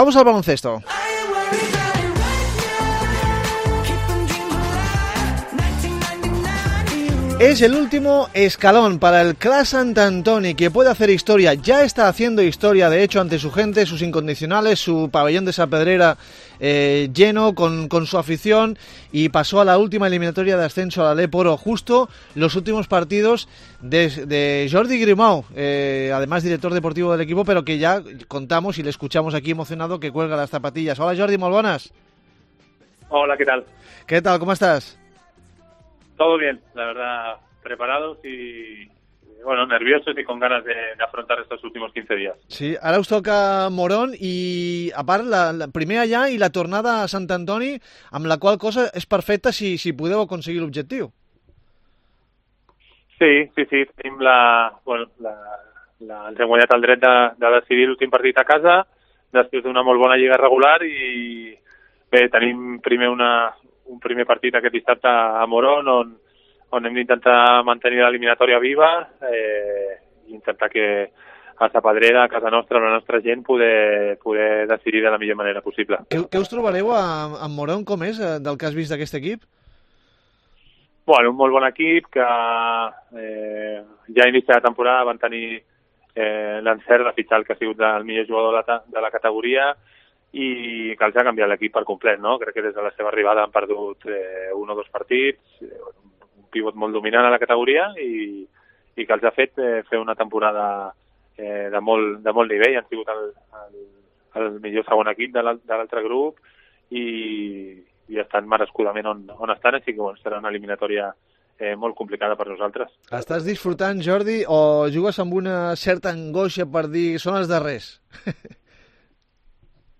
En esta entrevista hablamos con Grimau de sus sensaciones ante sus últimos dos partidos.